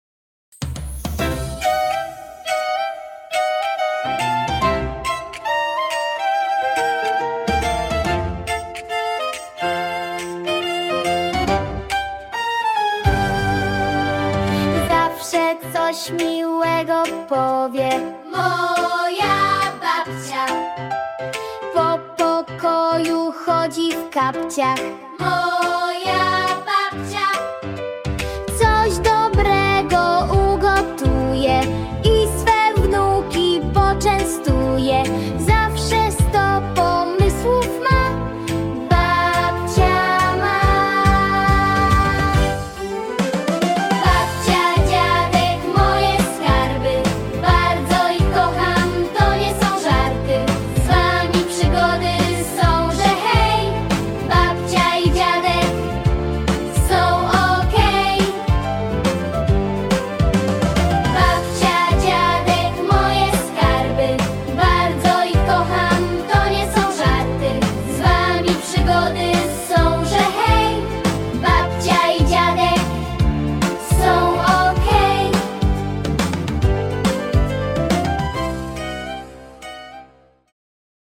dla dzieci 5–6 lat oraz klas 1–3